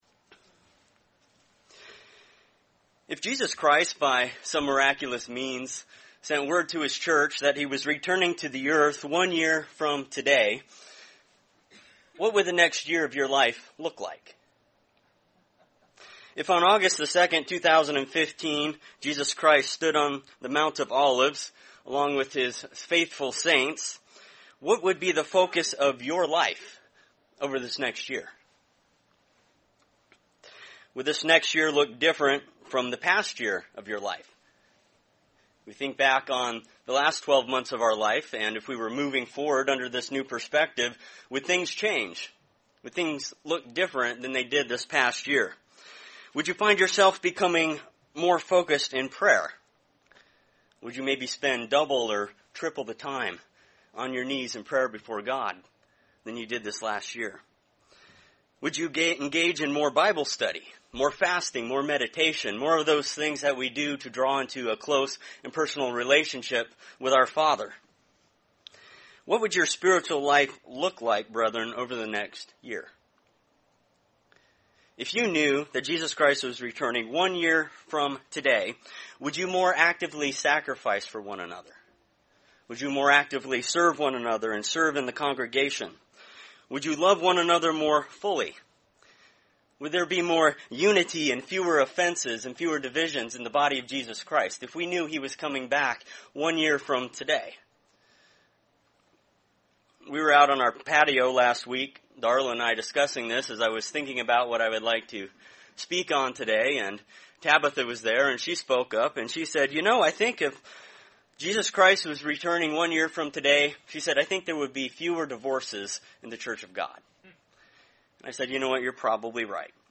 Sermon on Saturday, August 2, 2014 in Kennewick, Washington. Do not make the Kingdom of God the deadline by which you decide to live God's way. Wake up now and redeem the time so that when Christ returns, you will be found ready.